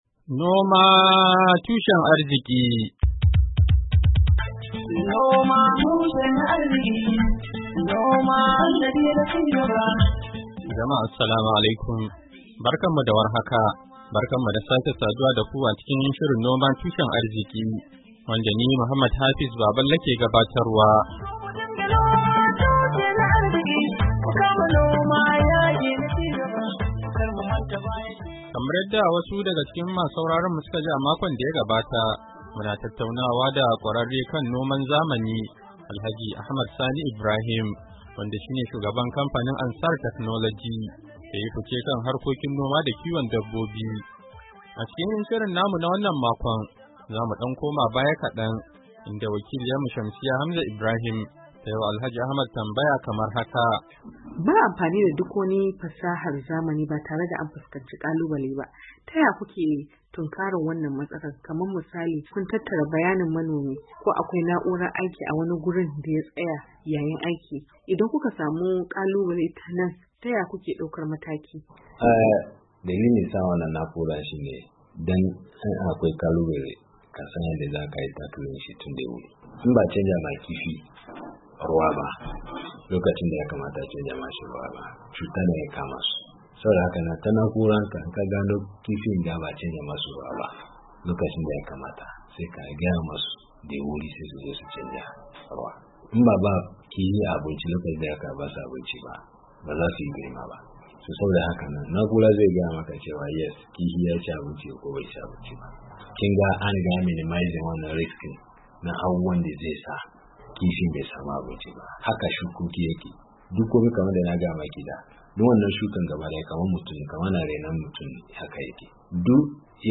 NOMA TUSHEN ARZIKI: Hira Da Kwararre Kan Noma Da Kiwon Dabbobi Na Zamani a Najeriya - Kashi Na Hudu - Janairu 24, 2023